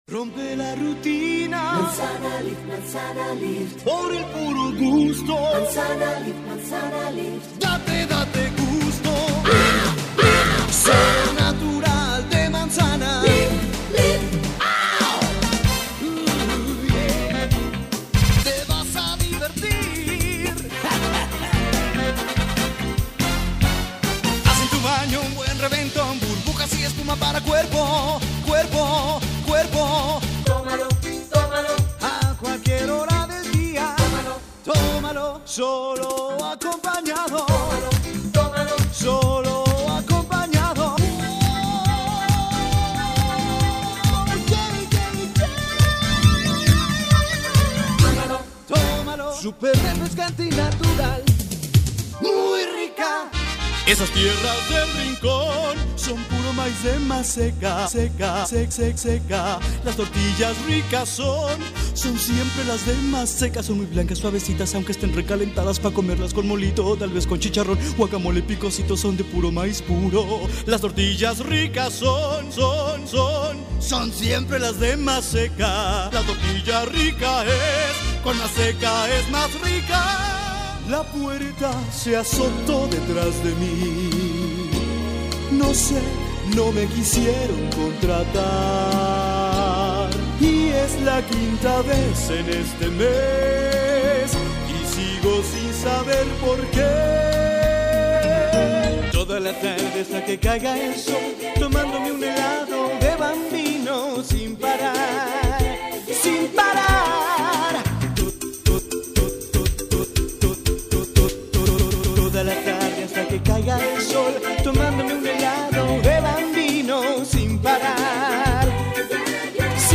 Masculino
Espanhol - México